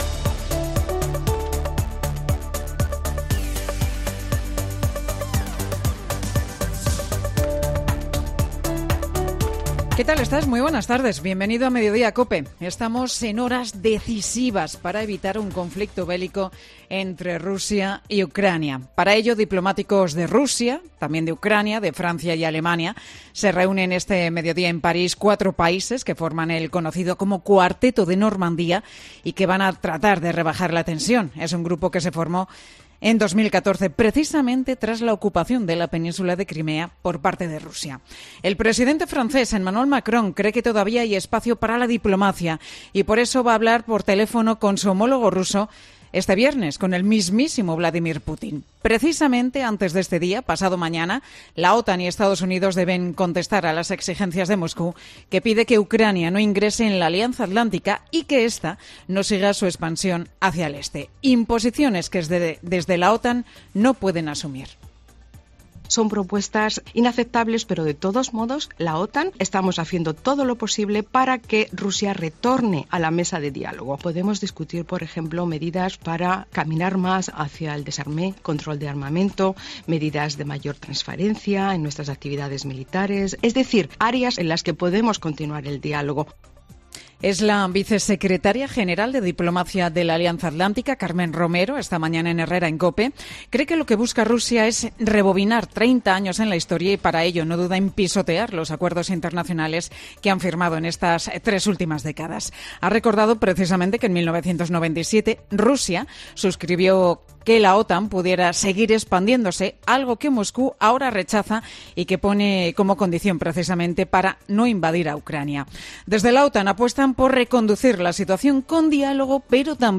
Monólogo de Pilar García Muñiz Pilar García Muñiz: "Roza el milagro.